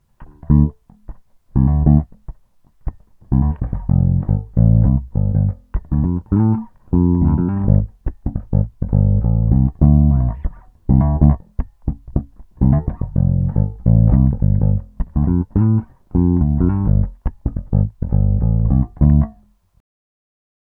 If your looking for a large full warm tone from your bass these are the pickups.
Super Neo – Finger style – Tone Down
Super-Neo-Fingerstyle-Tone-DOWN.m4a